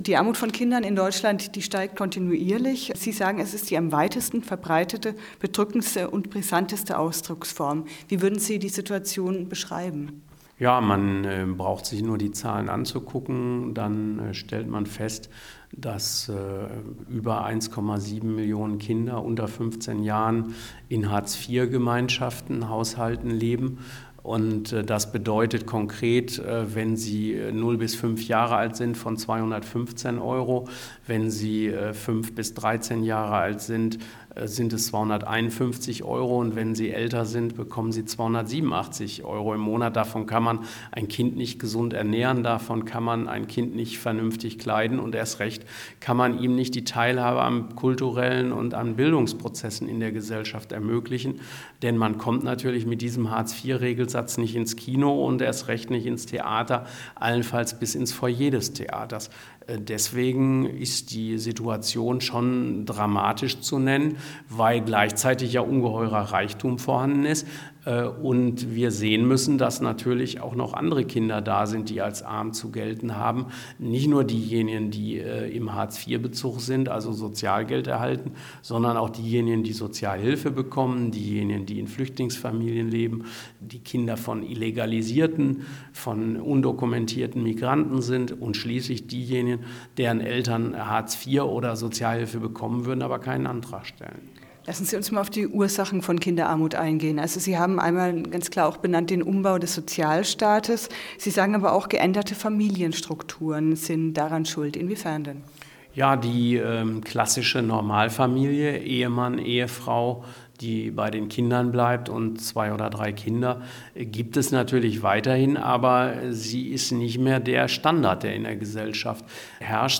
Und was für Folgen hat es für die betroffenen Kinder? Darüber sprechen wir mit Prof. Christoph Butterwegge, der seit vielen Jahren zu Kinderarmut forscht.